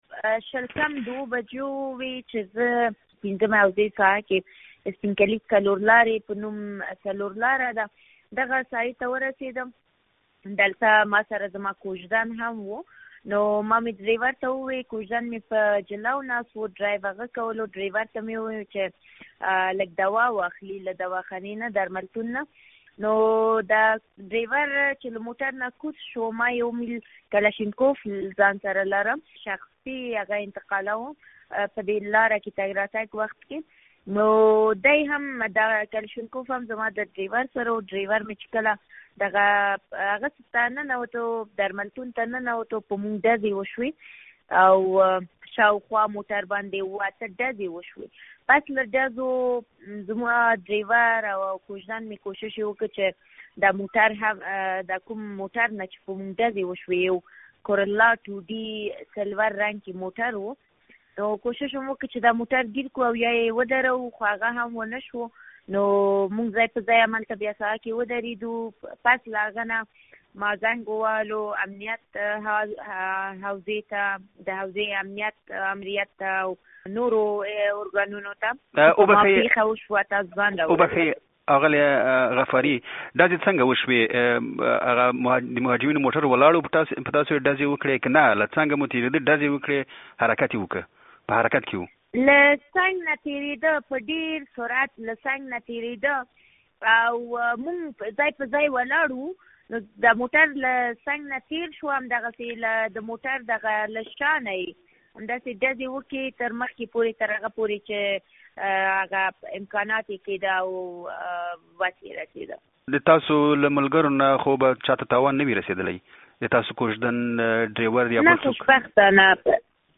له اغلې غفاري سره مرکه